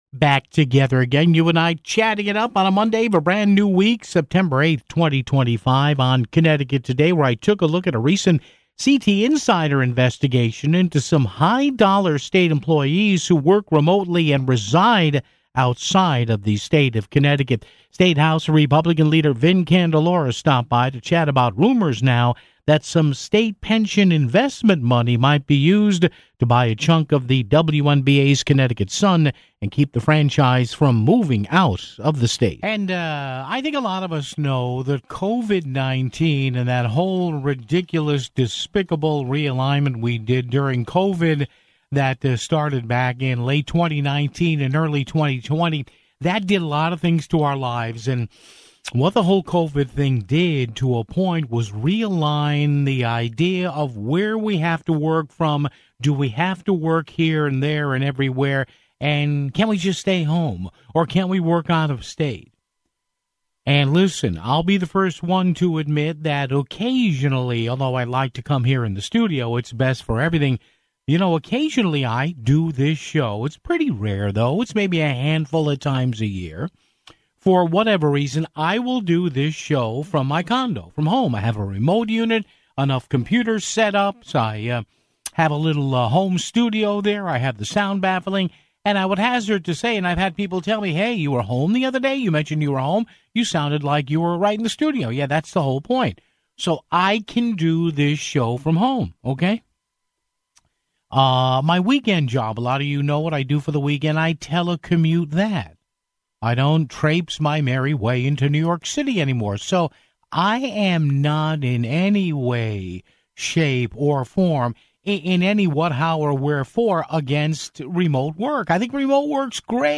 State House GOP leader Vin Candelora joined us to chat about rumors that state pension money might be used to invest in the WNBA's Connecticut Sun (16:53)